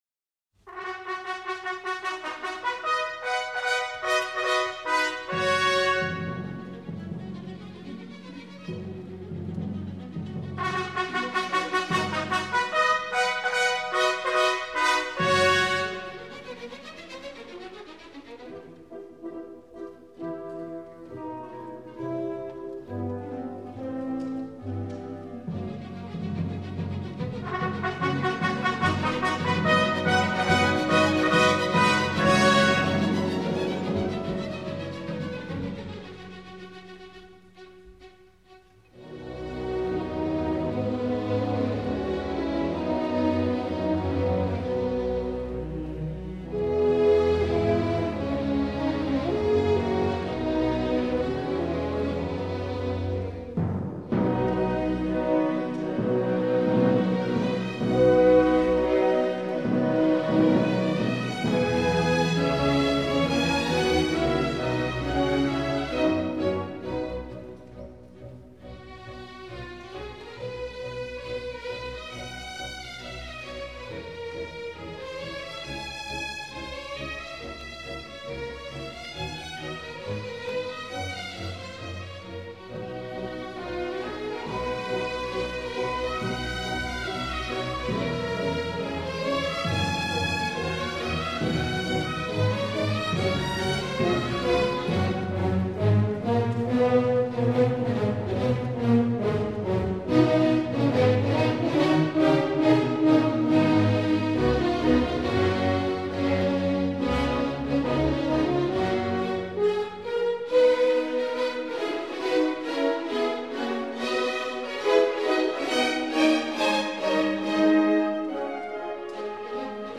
coros y orquestas interclásicoscon excelencia vocal